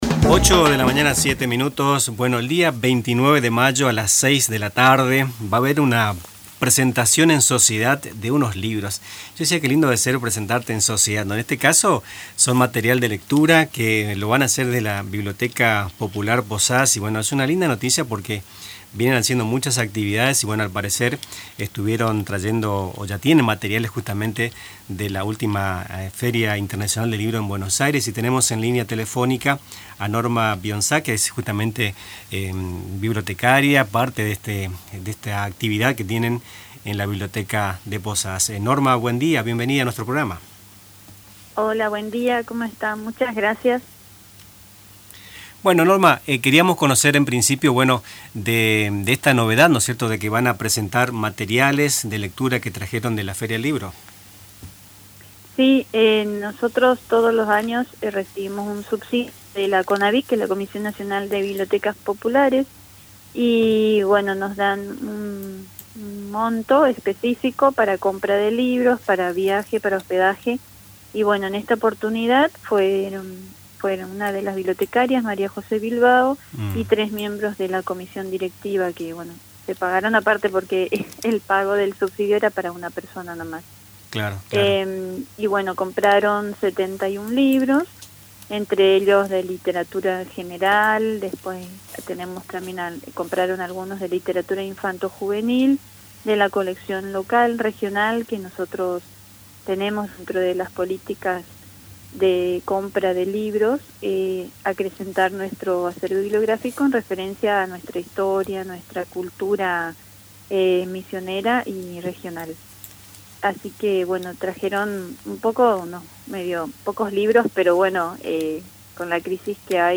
compartió detalles sobre este evento en una entrevista telefónica con Radio Tupa Mbae.